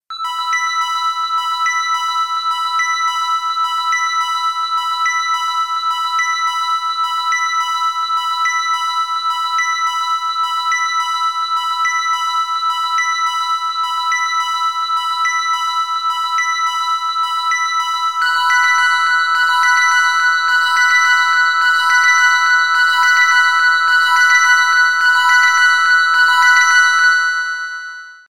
澄んだ音で心地よい目覚めを促します。